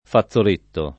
fazzoletto [ fa ZZ ol % tto ] s. m.